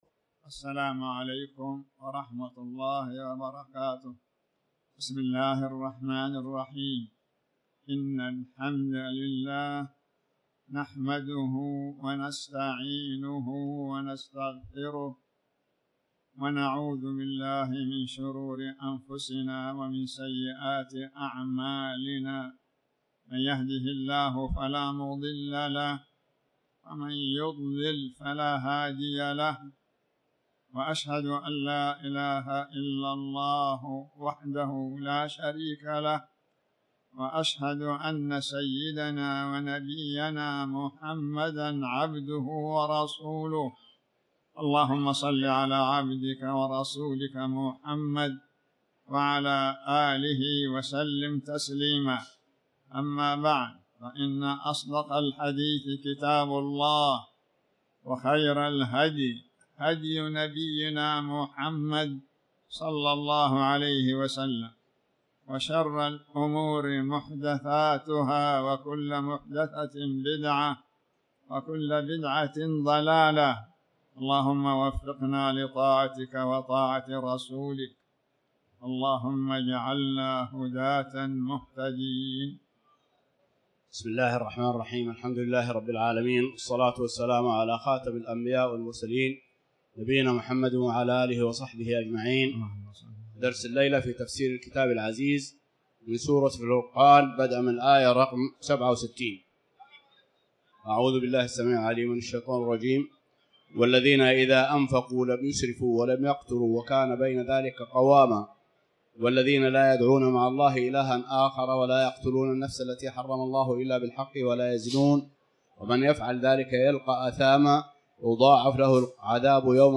تاريخ النشر ٢١ شوال ١٤٤٠ هـ المكان: المسجد الحرام الشيخ